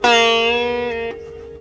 SITAR LINE56.wav